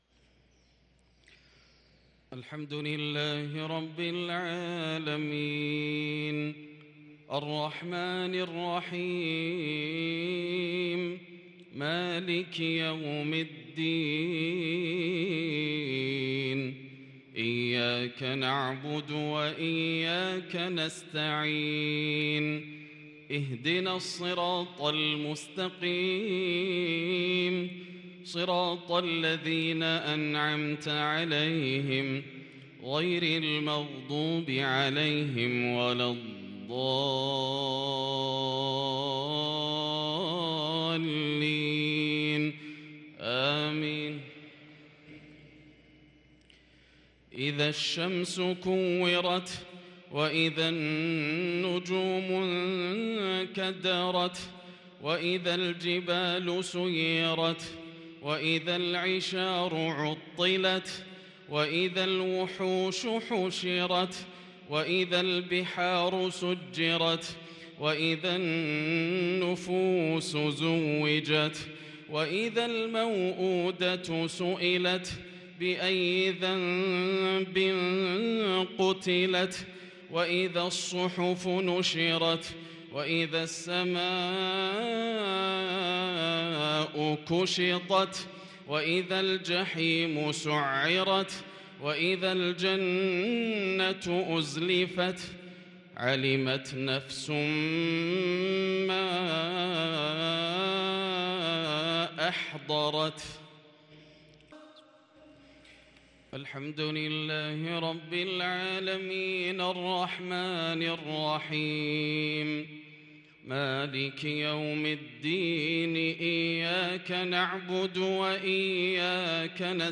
صلاة المغرب للقارئ ياسر الدوسري 11 جمادي الأول 1444 هـ
تِلَاوَات الْحَرَمَيْن .